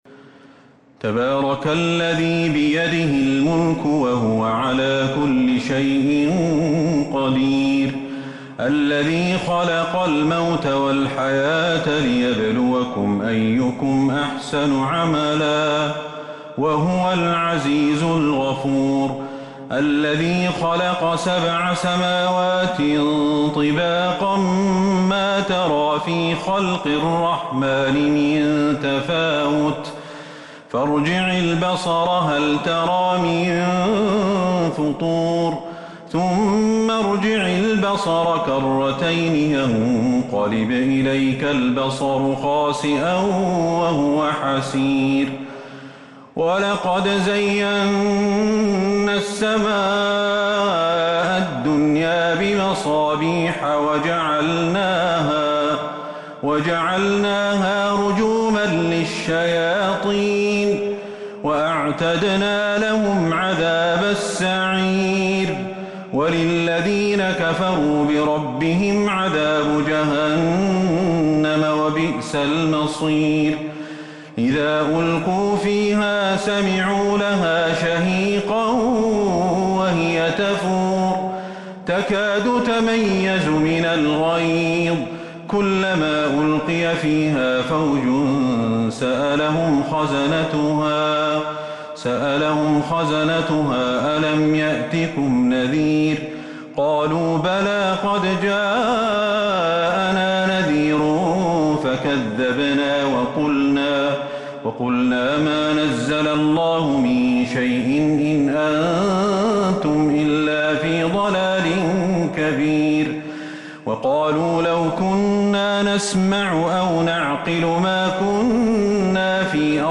سورة الملك Surat Al-Mulk من تراويح المسجد النبوي 1442هـ > مصحف تراويح الحرم النبوي عام 1442هـ > المصحف - تلاوات الحرمين